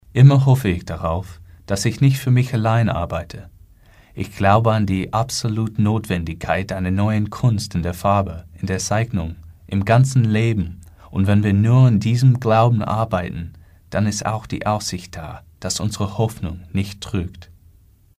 Native Speaker English US Sprecher
Voice Over